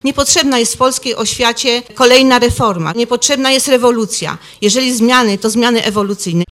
– Zbyt szybko czyli niedbale były też przygotowywane programy nauczania i nowe podręczniki – mówi posłanka Elżbieta Gapińska